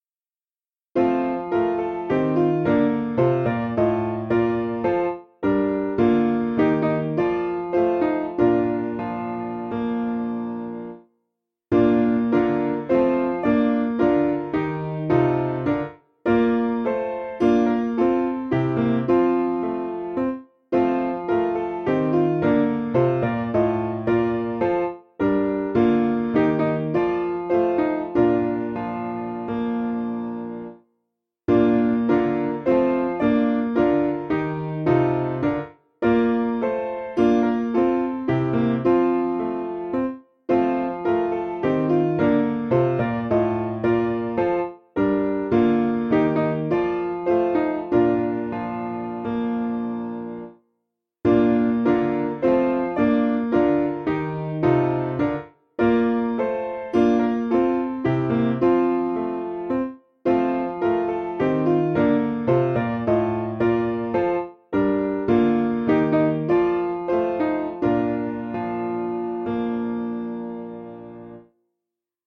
Simple Piano
(CM)   3/Bb 361kb